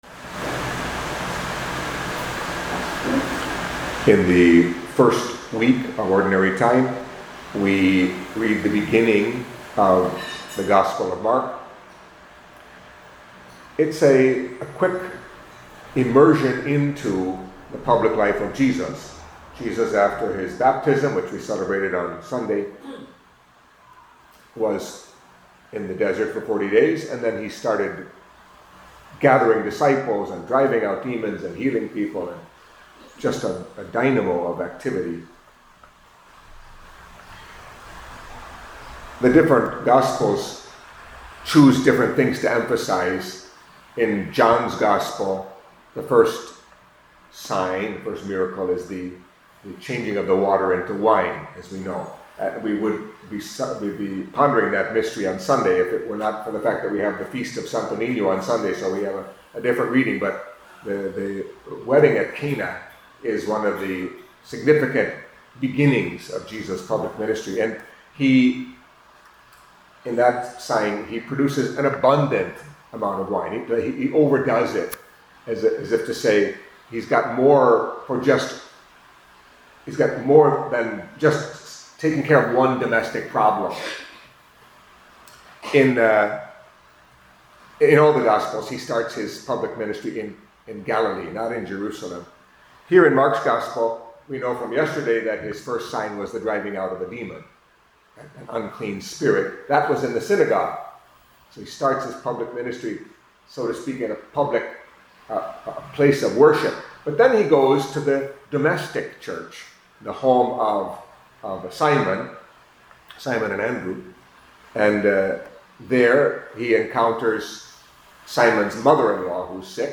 Catholic Mass homily for Wednesday of the First Week in Ordinary Time